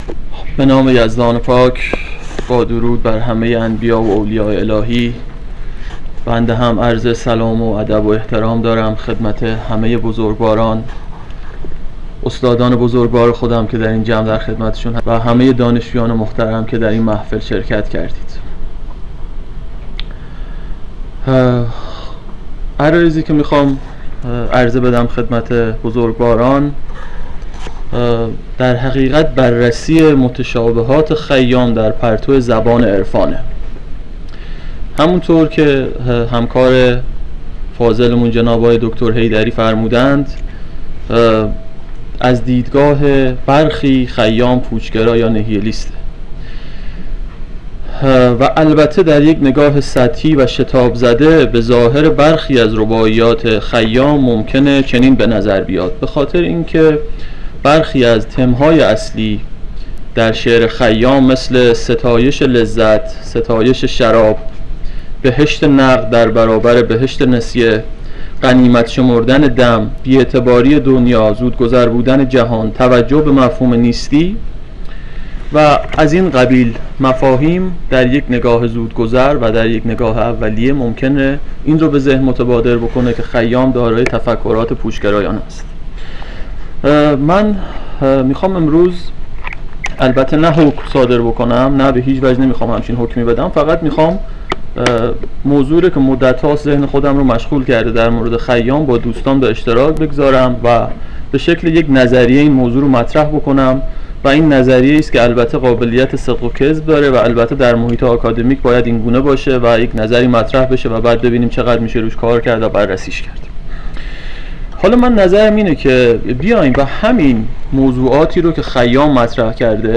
مراسم نکوداشت حکیم خیام نیشابوری
دانلود سخنرانی مراسم نکوداشت حکیم خیام نیشابوری